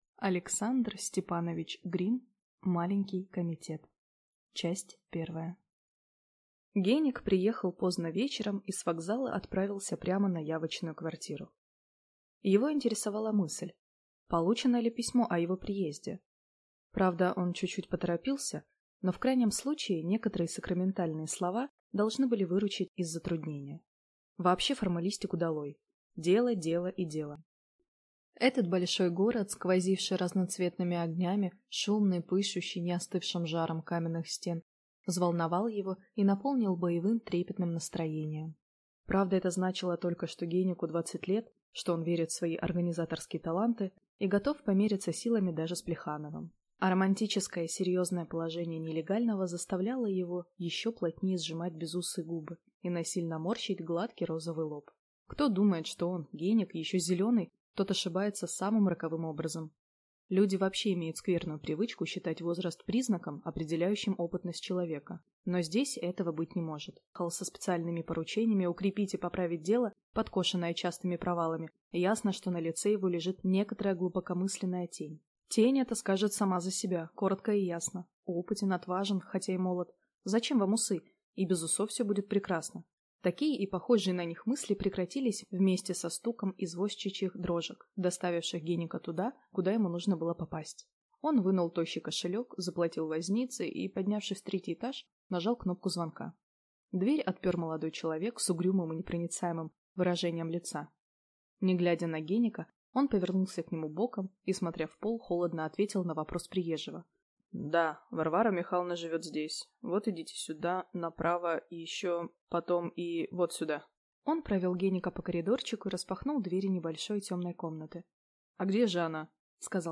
Аудиокнига Маленький комитет | Библиотека аудиокниг